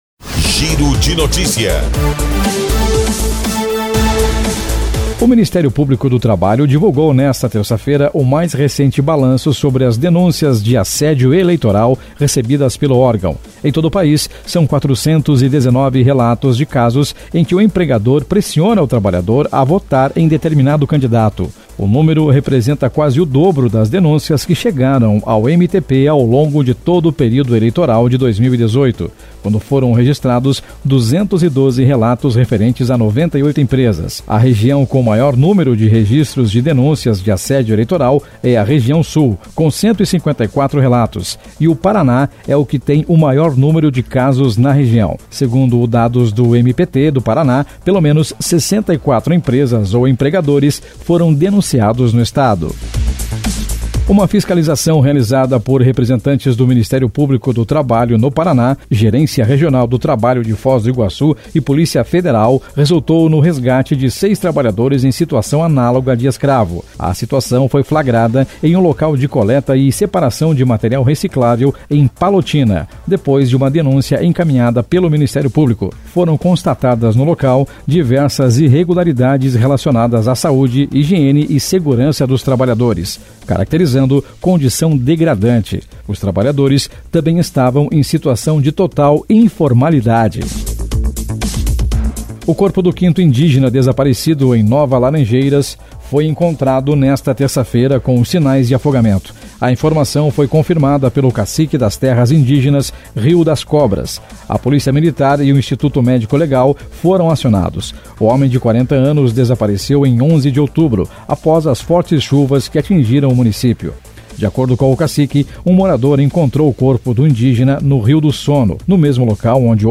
Giro de Notícias Manhã